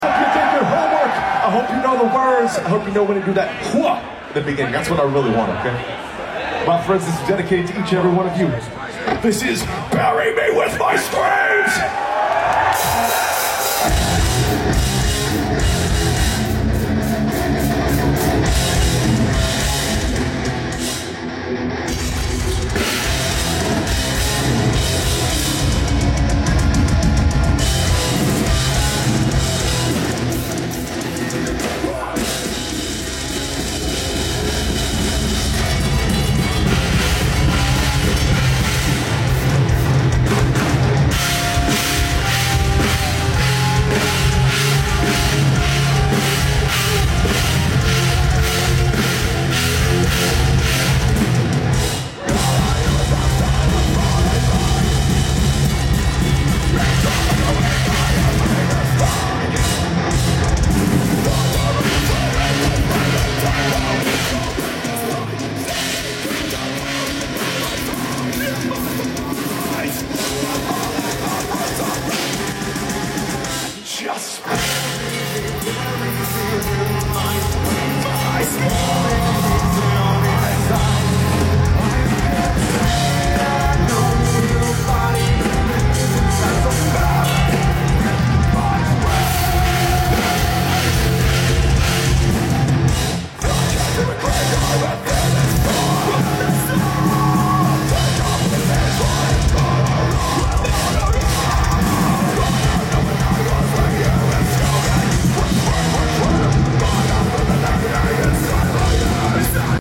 Listen to this damn tone! Taken from an iPhone mic at that!